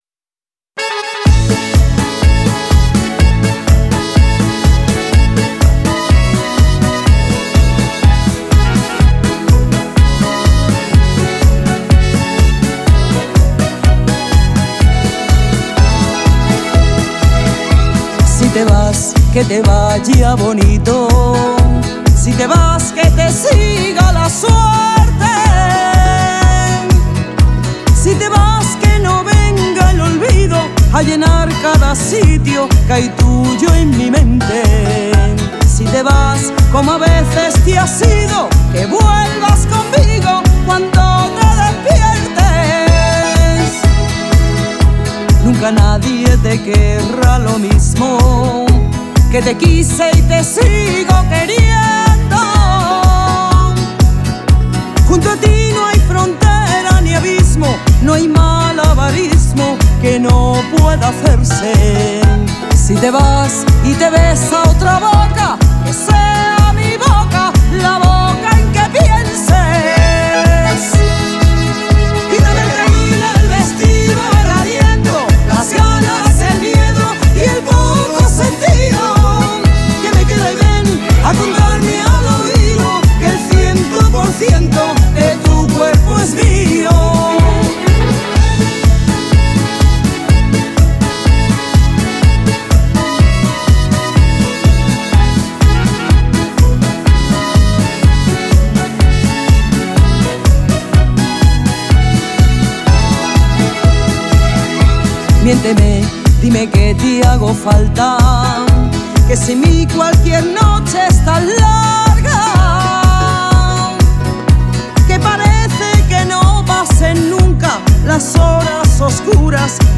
Cantante (música ligera)